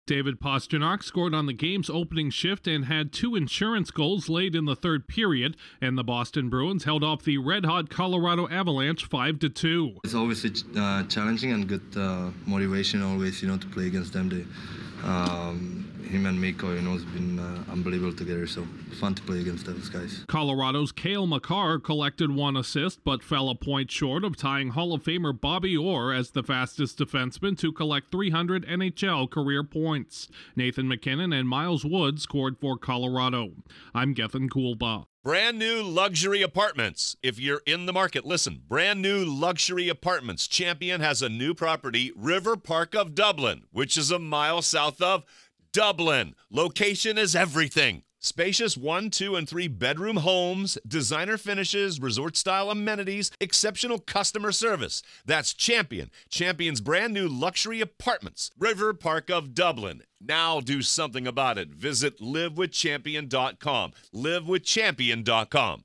David Pastrnak out-dueled Nathan MacKinnon and Cale Makar with his 16th career hat trick to lift the Bruins over the Avalanche. Correspondent